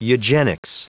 Prononciation du mot eugenics en anglais (fichier audio)
Prononciation du mot : eugenics